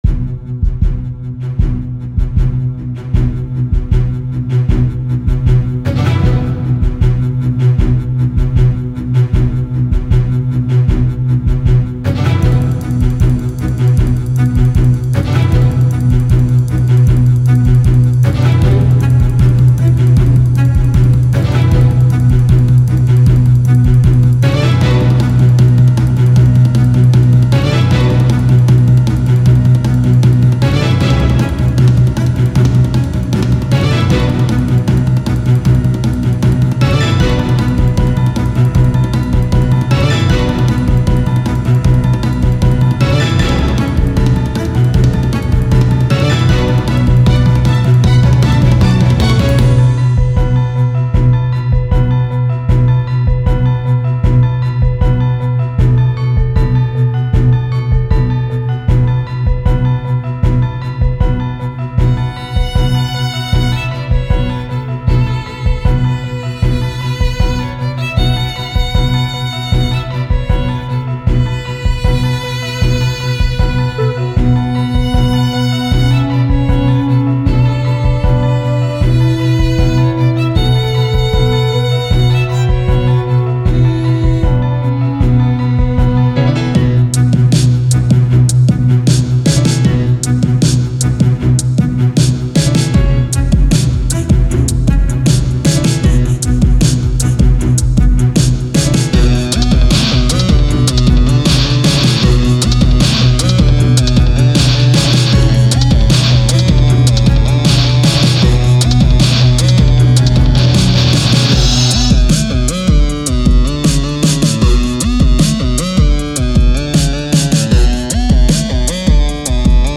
Je partage tout de même la BO que j'avais spécialement composé pour ce mono, parce que la garder pour moi serait un peu dommage compte tenu du temps passé dessus (j'en suis plutôt content, ce qui est rare ^^)...